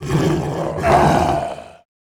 dead_2.wav